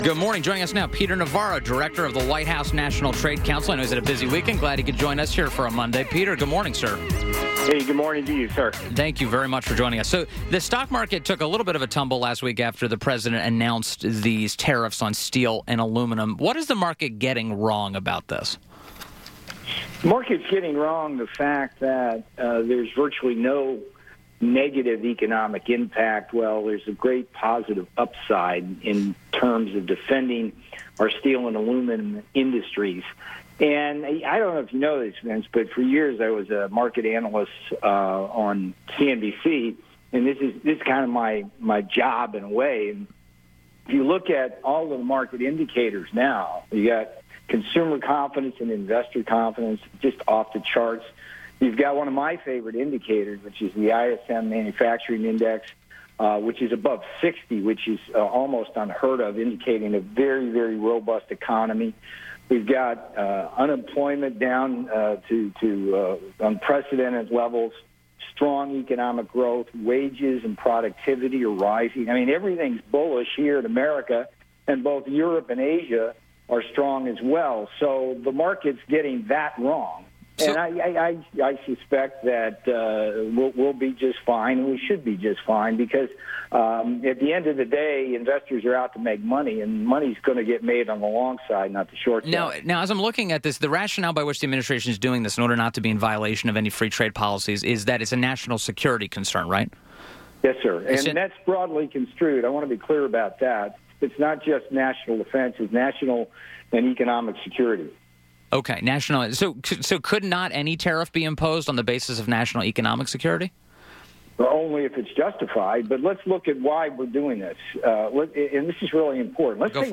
INTERVIEW - PETER NAVARRO - Director of the White House National Trade Council – discussed President Trump’s proposed steel and aluminum trade tariffs